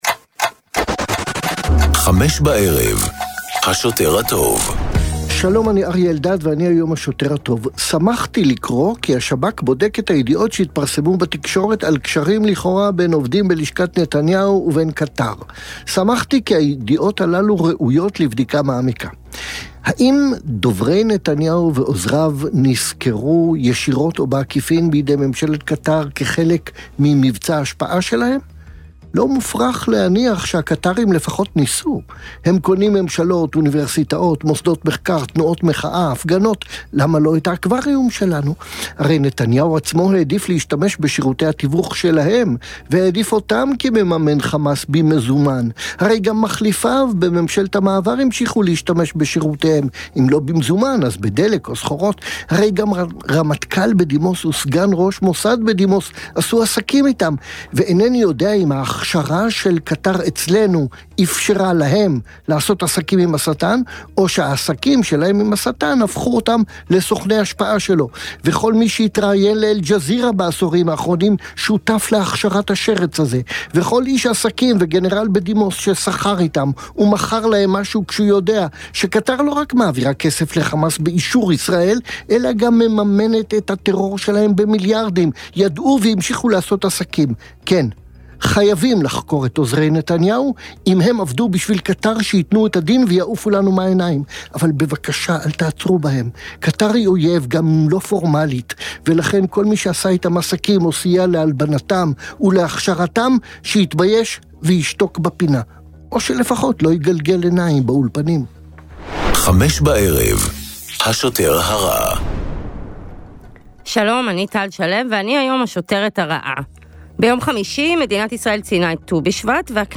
בן כספית, מהעיתונאים הבכירים והבולטים כיום במדינת ישראל, ופרופסור אריה אלדד, רופא, פובליציסט וכמובן חבר כנסת לשעבר מטעם האיחוד הלאומי ועוצמה לישראל, מגישים יחד תכנית אקטואליה חריפה וחדה המורכבת מריאיונות עם אישים בולטים והתעסקות בנושאים הבוערים שעל סדר היום.